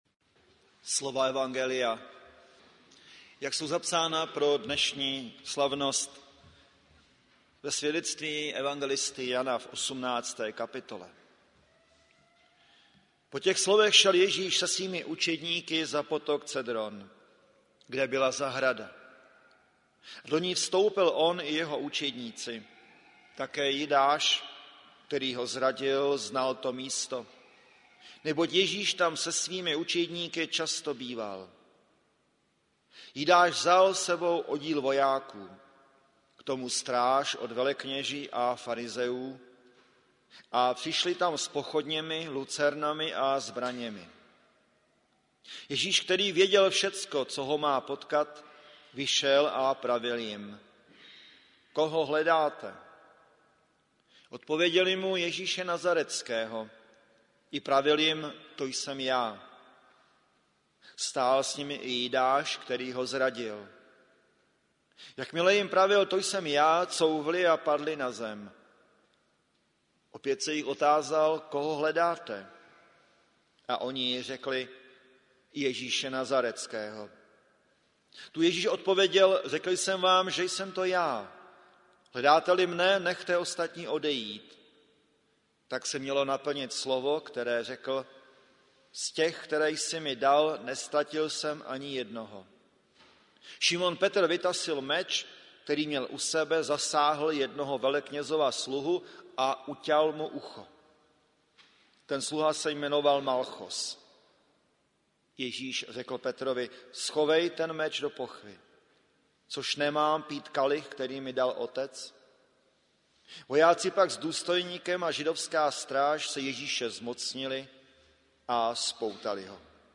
Záznam kázání z bohoslužeb na Velký pátek konaných v evangelickém chrámu Mistra Jana Husi v Plzni.